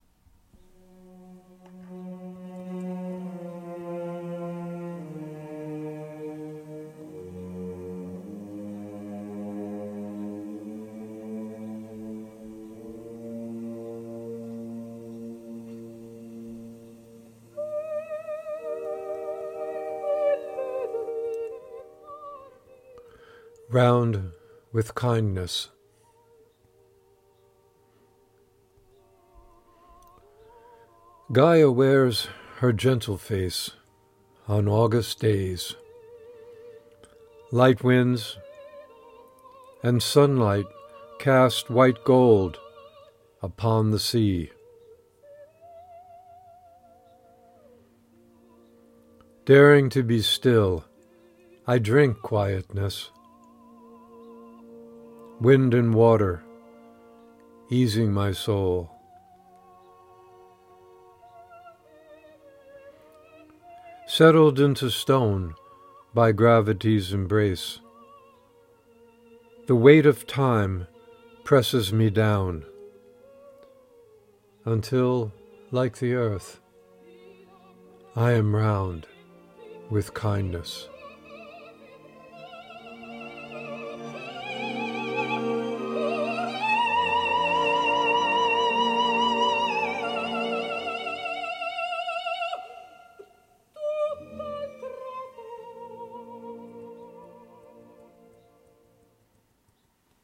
Reading of “Round with Kindness” with music by Maria Callas